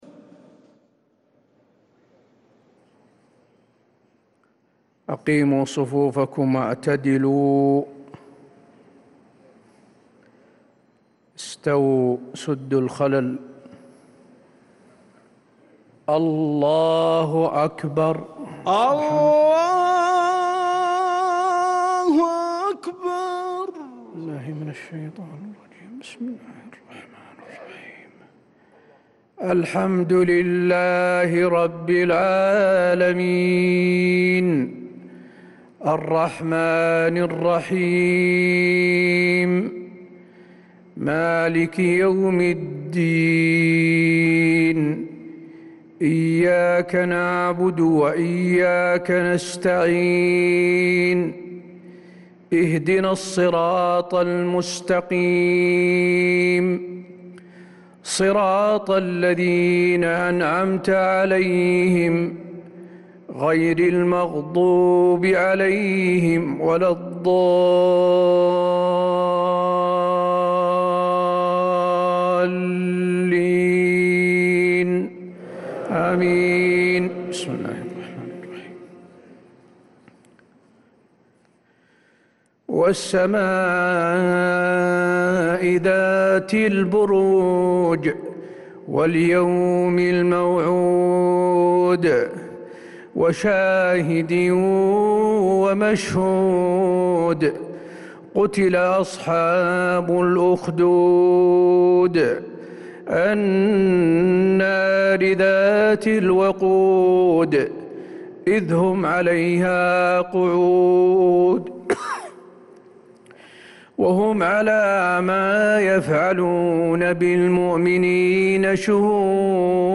عشاء الجمعة 4 ذو القعدة 1446هـ سورتي البروج و الطارق كاملة | Isha prayer from Surah Al-Burooj and At-Tariq 2-5-2025 > 1446 🕌 > الفروض - تلاوات الحرمين